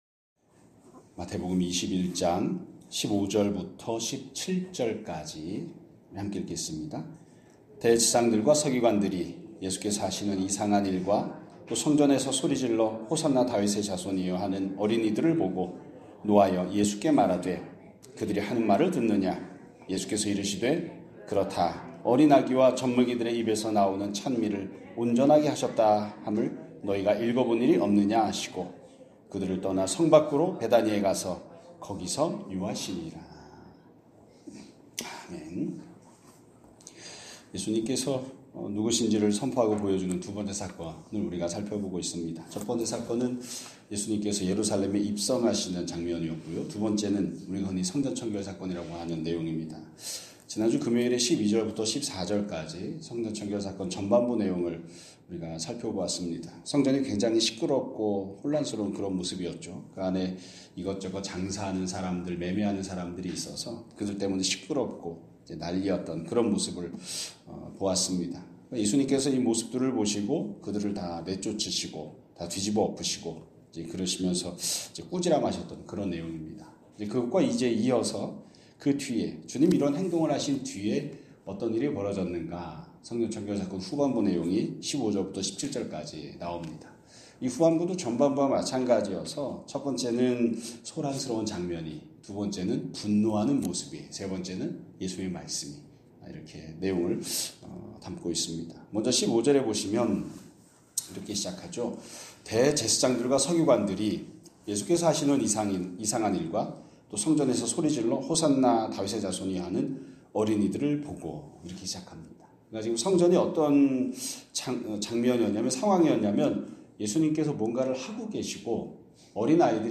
2026년 1월 26일 (월요일) <아침예배> 설교입니다.